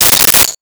Sword Swipe 01
Sword Swipe 01.wav